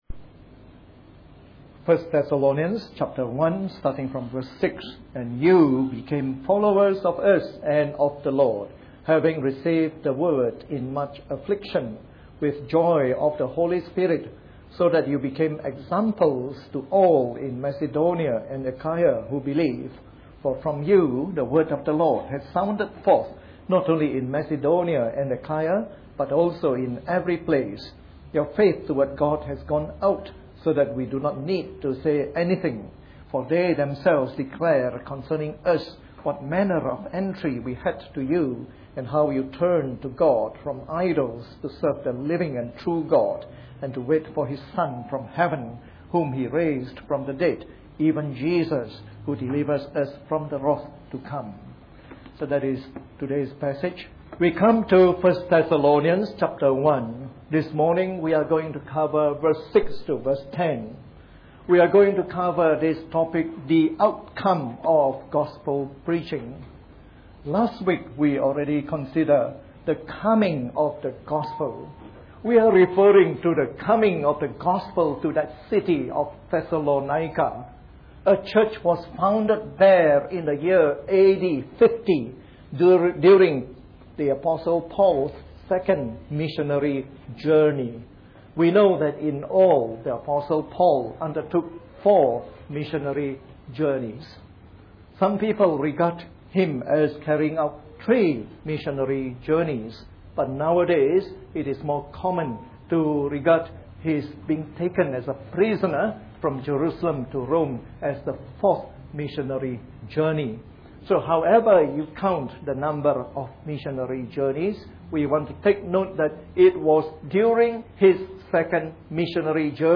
A sermon in the morning service from our series on 1 Thessalonians.